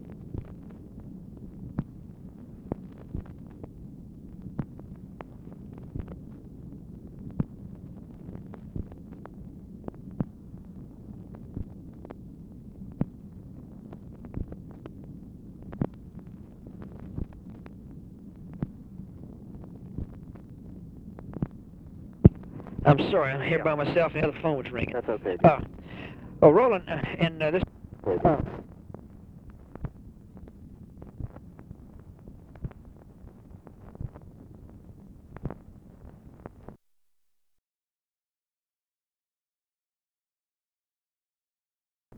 CANNOT DETERMINE TOPIC; NO SIGNIFICANT CONVERSATION RECORDED